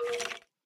skeleton1.ogg